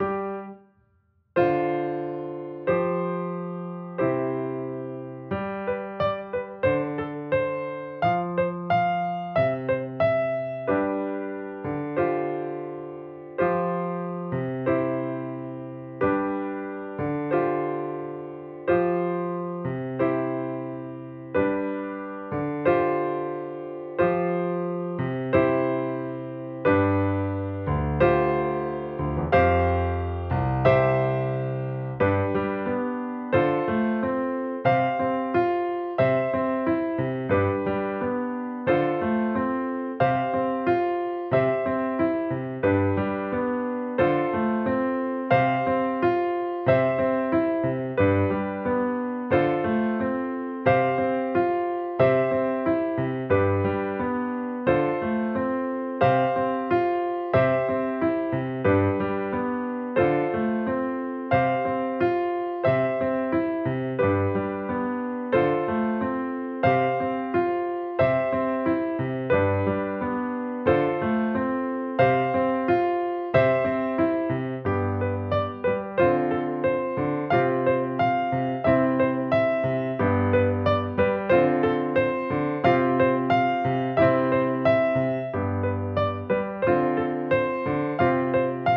• piano accompaniment